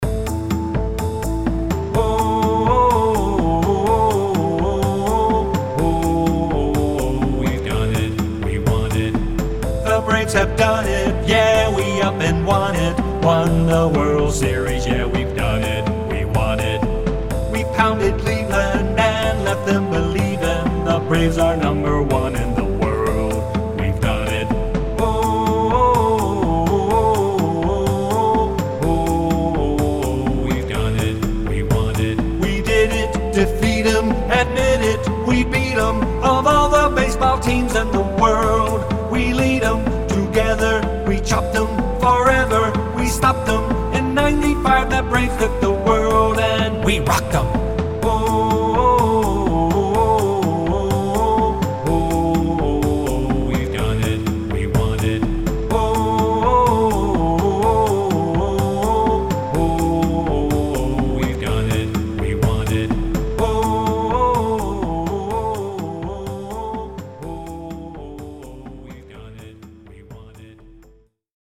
Promotional/Theme Songs